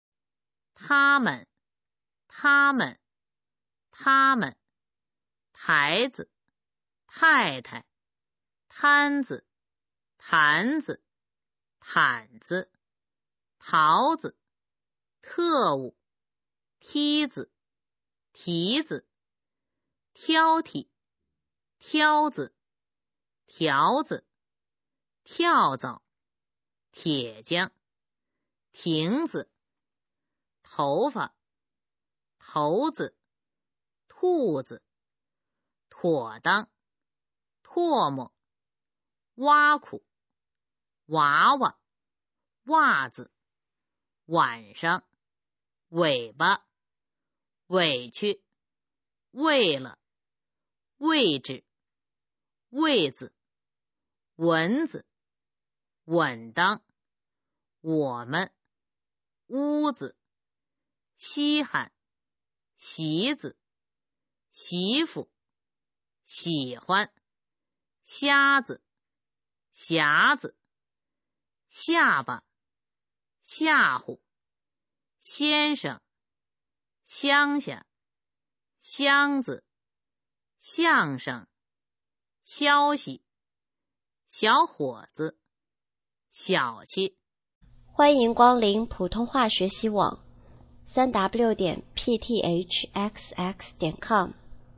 普通话水平测试用必读轻声词语表示范读音第401-450条